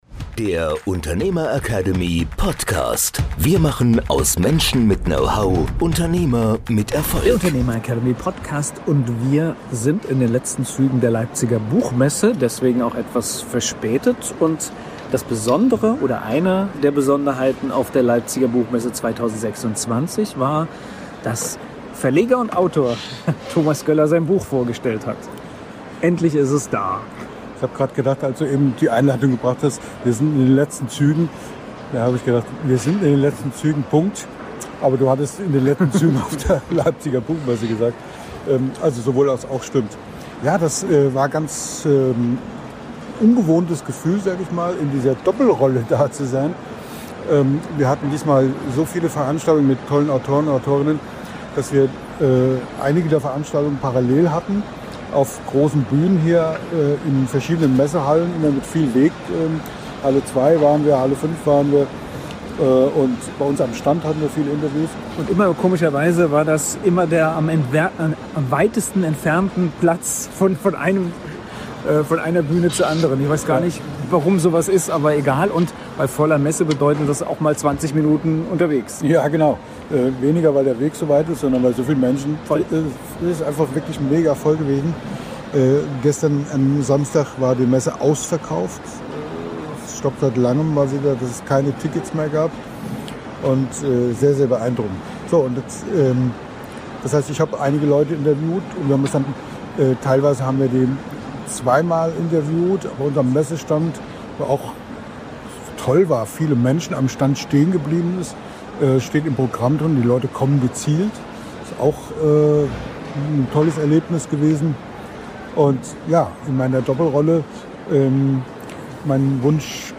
In dieser direkt auf der Messe aufgenommenen Folge ziehen wir ein